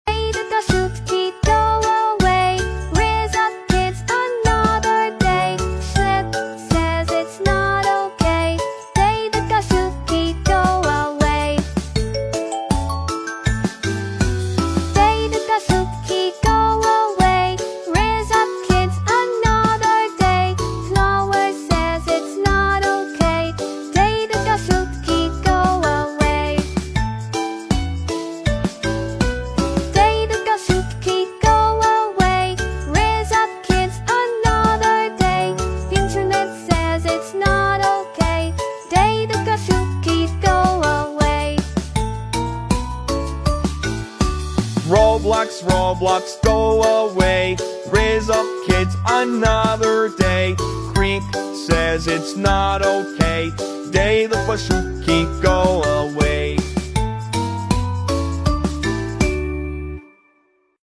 David Baszucki Go Away Meme sound effects free download
David Baszucki Go Away Meme Sound Effect